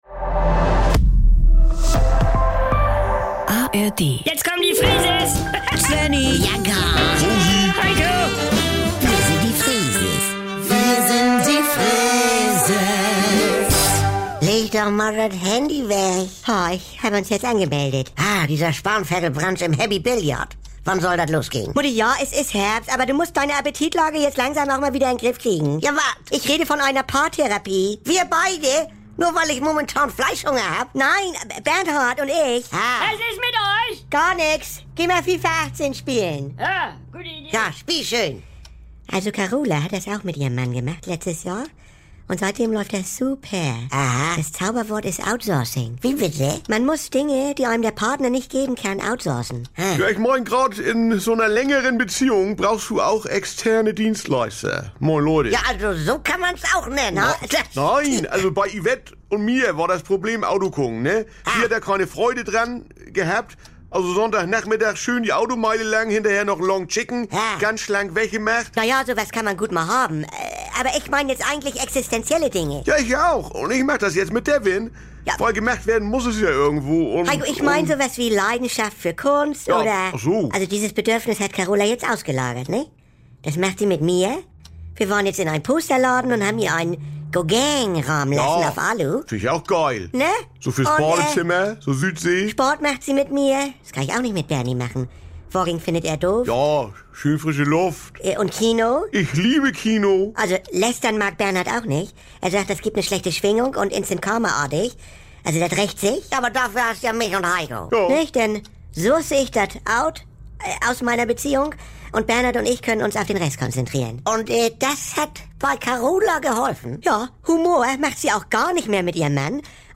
NDR 2 Saubere Komödien Unterhaltung Komödie NDR Freeses Comedy